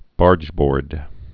(bärjbôrd)